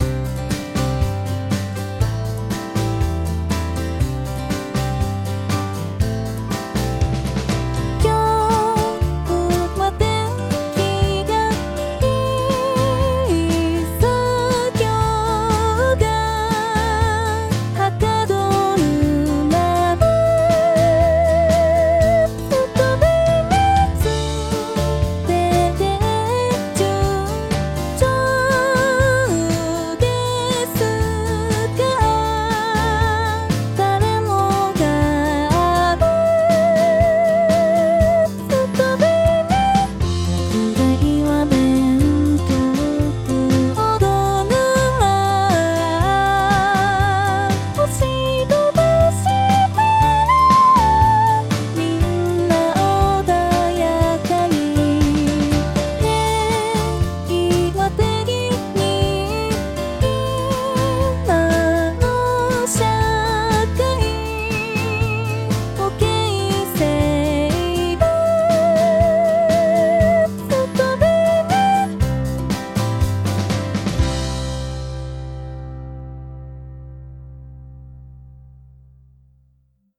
※Band-in-a-Boxによる自動作曲
※注 サビの高音はC5(ド)からG5(ソ)。その他の最高音はC6(ド)で歌声の限界値まで声を出させてます。
歌(104曲)